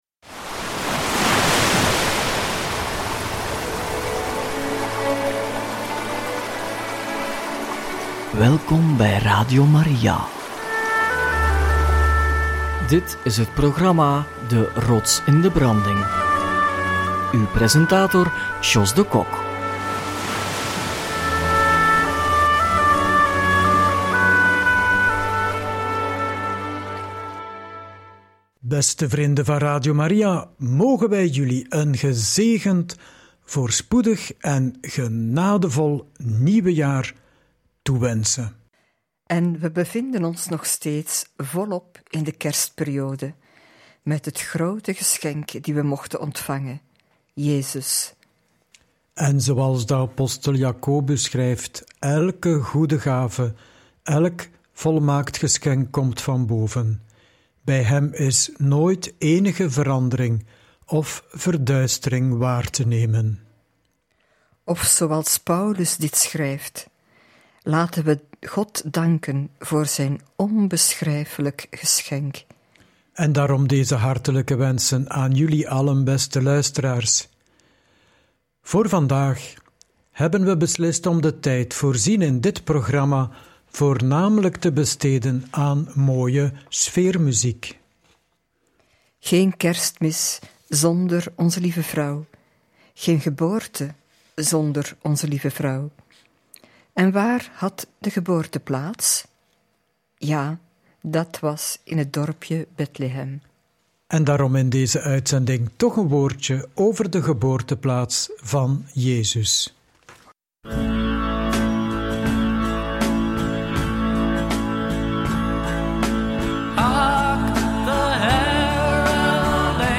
Een kerst- en nieuwjaarsspecial met vooral veel muziek!